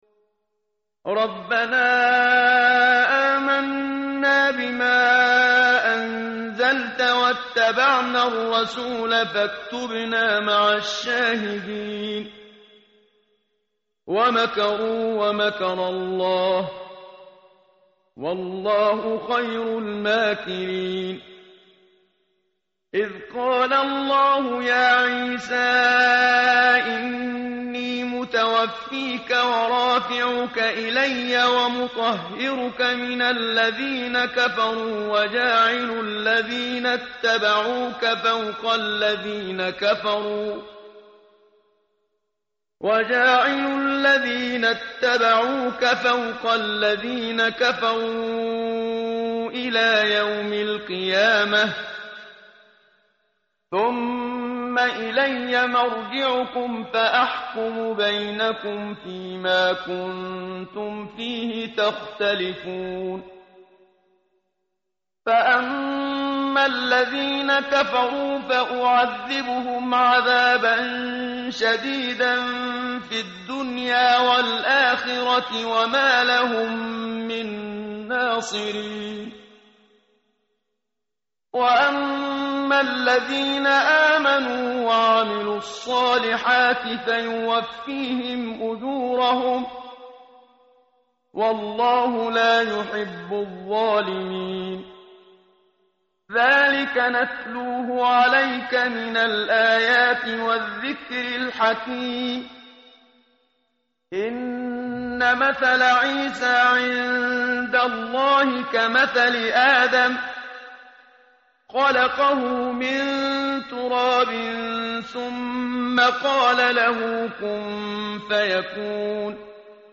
tartil_menshavi_page_057.mp3